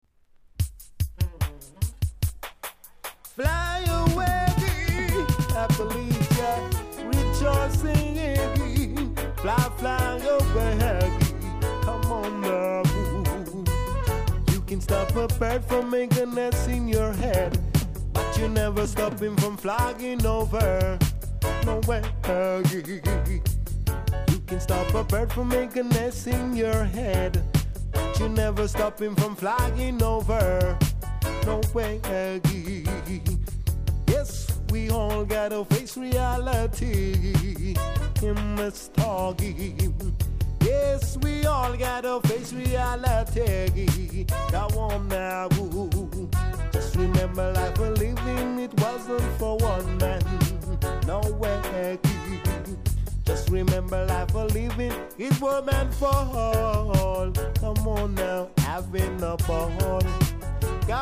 ※多少小さなノイズはありますが概ね良好です。